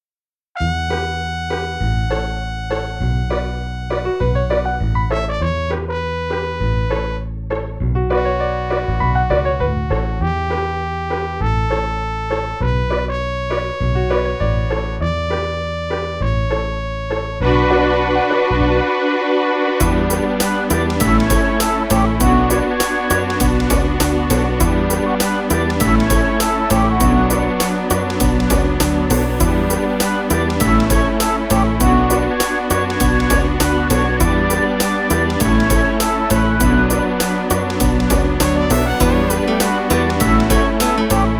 Midi